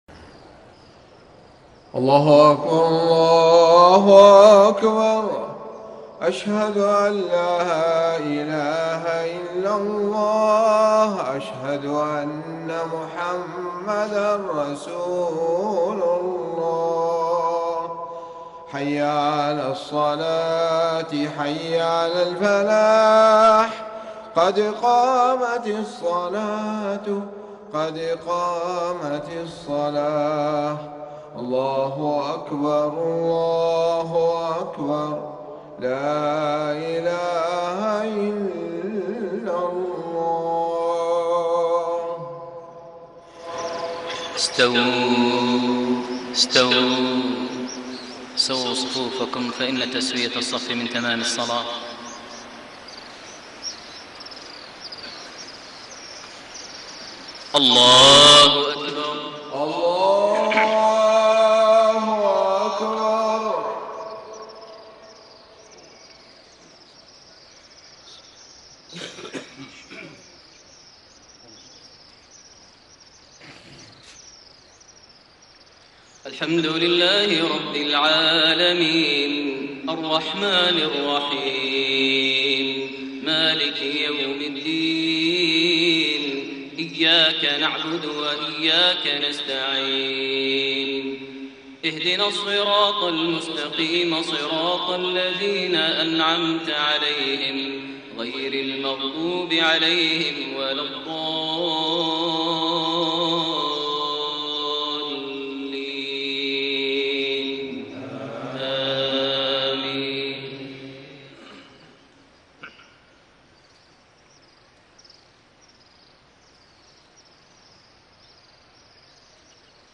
صلاة المغرب 7-6-1432 | من سورة الحديد 18-24 > 1432 هـ > الفروض - تلاوات ماهر المعيقلي